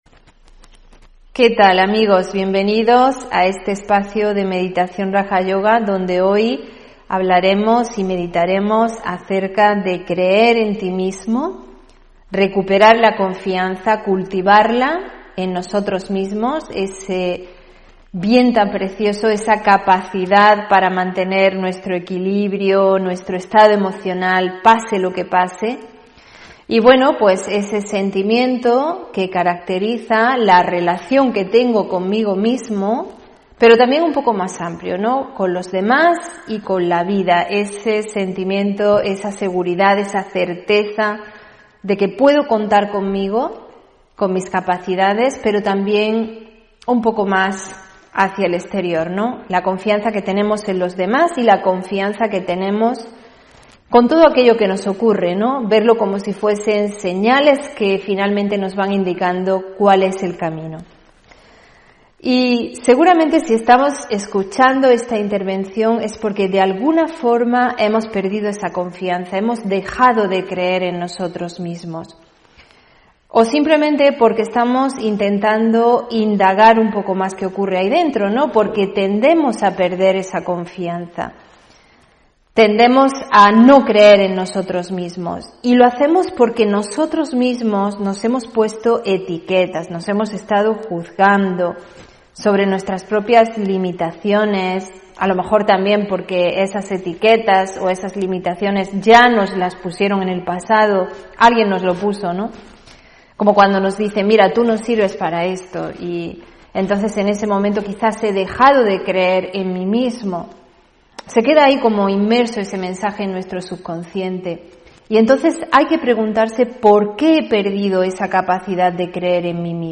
Meditación y conferencia: Creer en ti (21 Enero 2022)